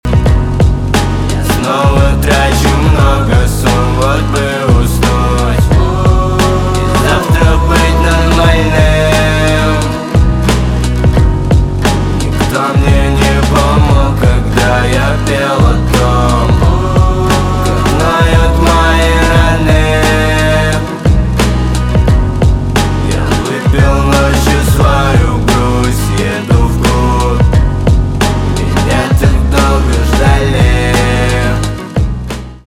альтернатива
гитара , спокойные , барабаны , грустные
печальные